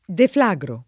deflagrare v.; deflagro [ defl #g ro ]